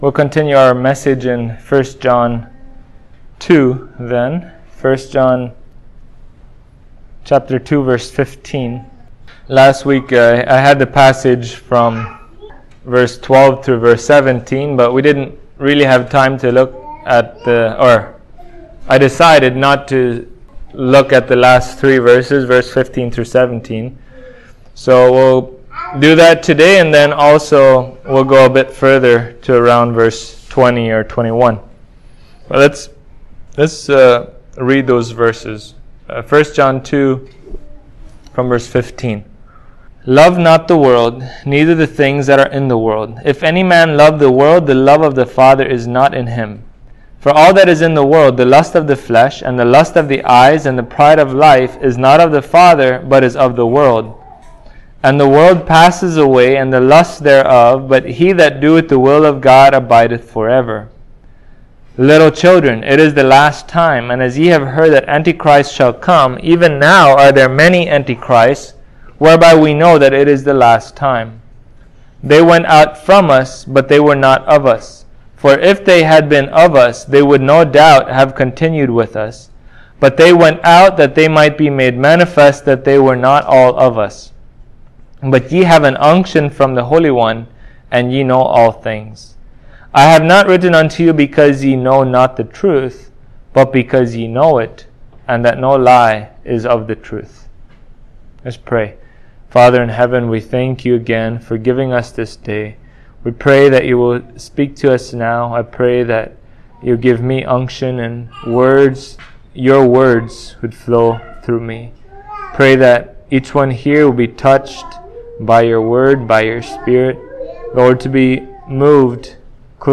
1John 2:20-29 Service Type: Sunday Morning Those who make themselves friends of the world